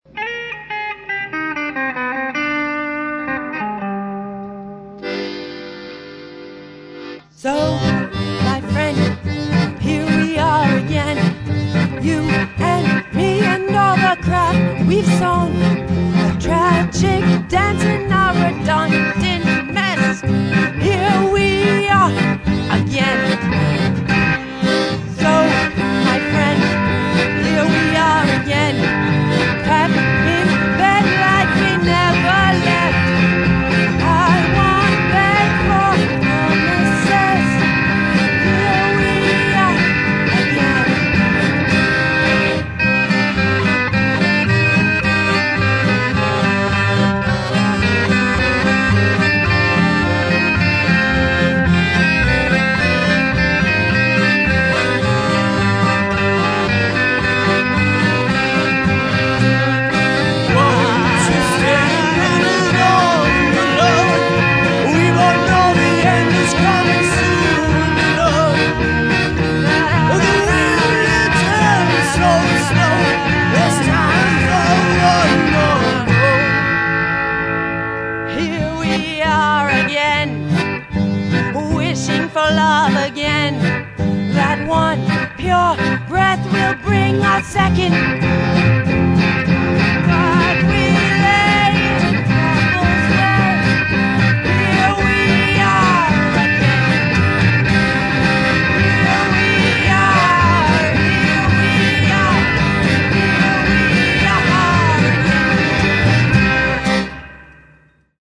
A band I produceed in a 'cabin on wheels', in New Orleans.